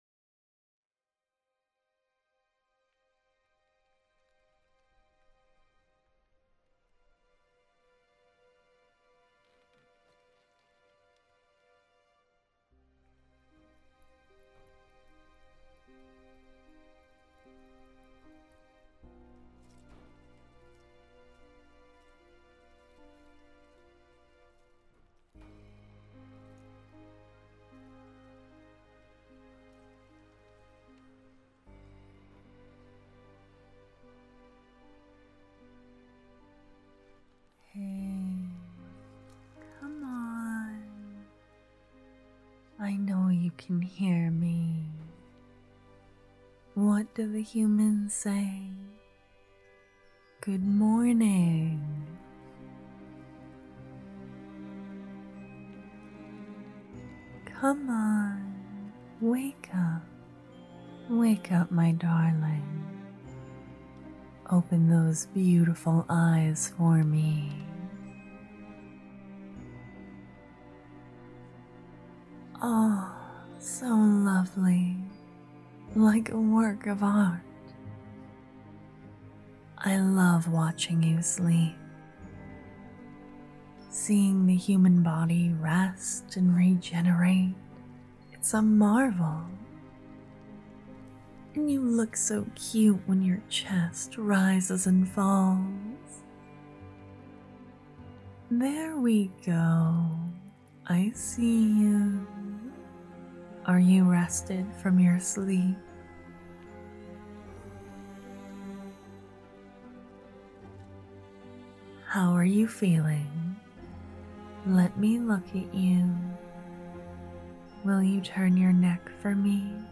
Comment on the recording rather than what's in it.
This one is quite loud so it can work on your speaker, and it's a bit spooky~!